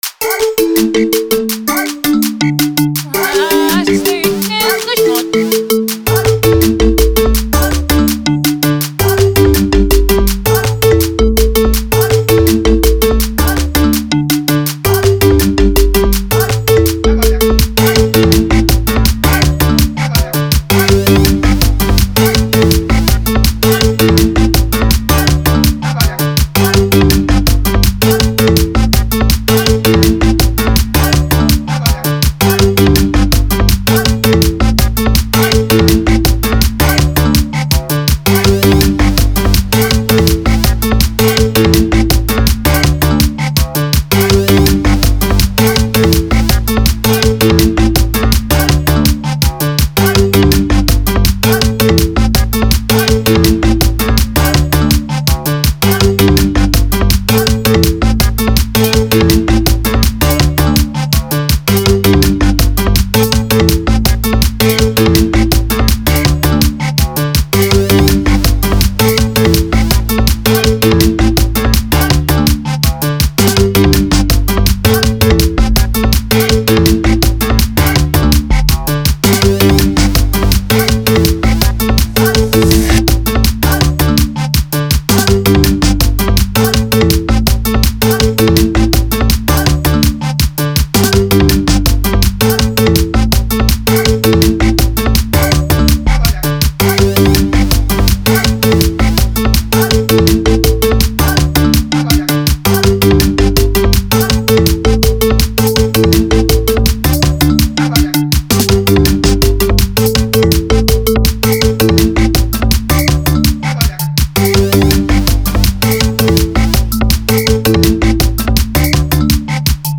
04:23 Genre : Xitsonga Size